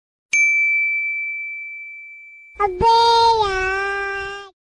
Cute Funny Sms Ringtone Free Download
• Soft and Sweet Notification Sound
• Short and Clear Sound